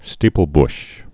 (stēpəl-bsh)